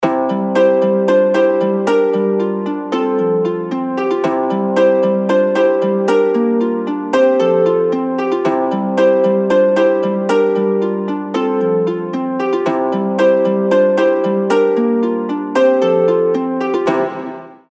• Качество: 320, Stereo
мелодичные
без слов
арфа